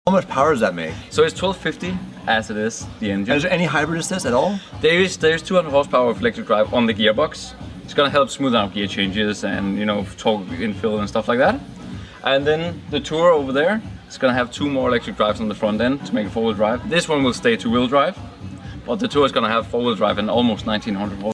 Insane Power_ Engine & Electric